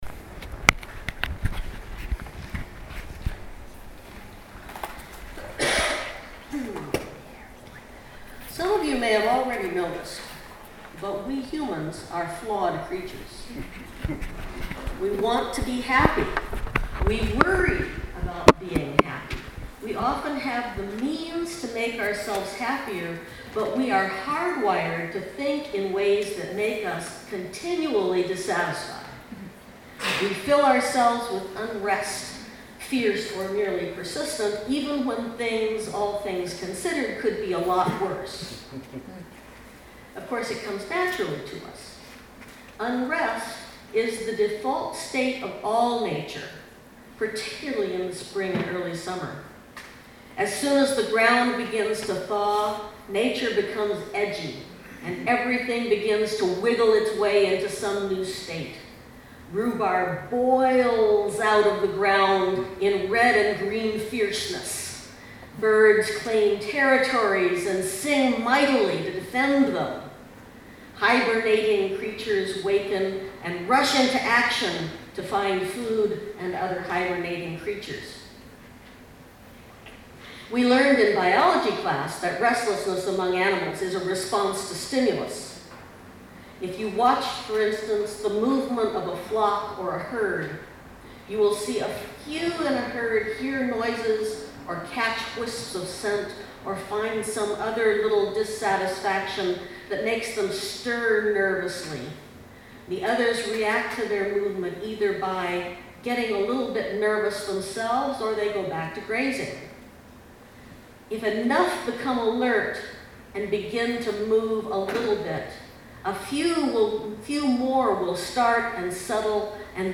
Posted in Sermons | Leave a Comment »